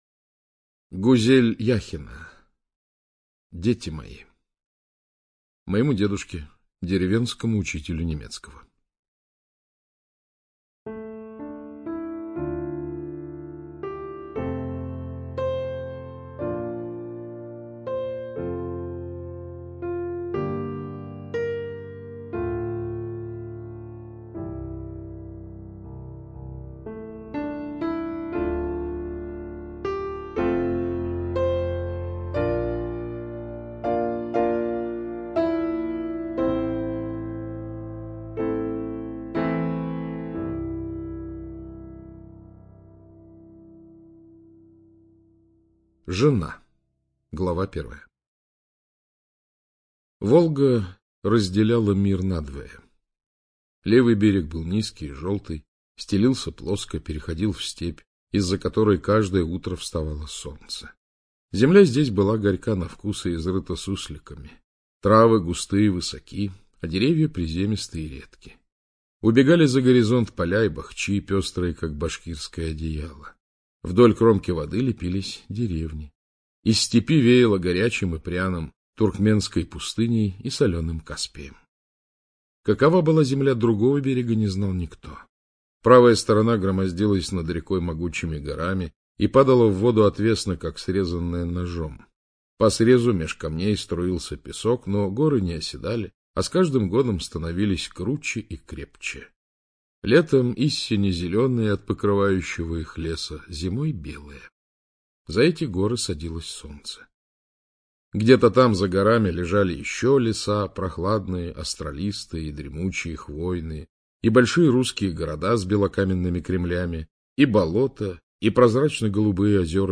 ЧитаетКлюквин А.
ЖанрСовременная проза